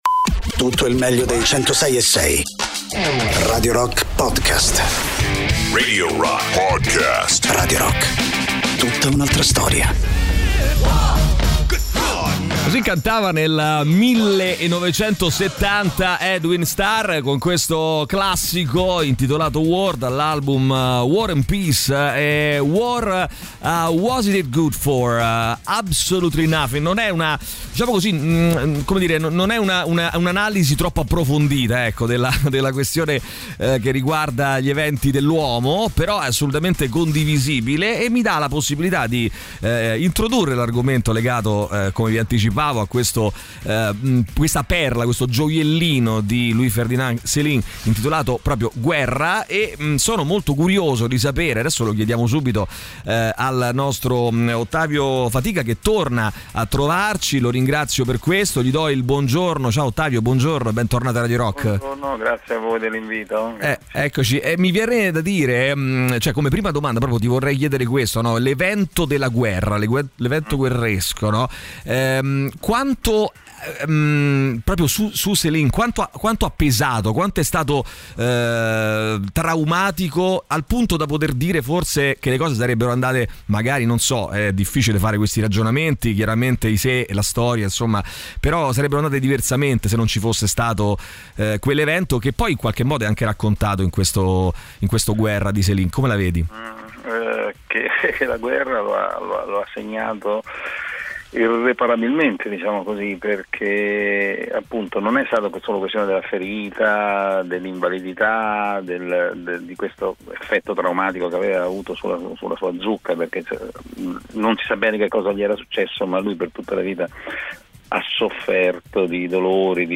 Interviste
ospite telefonico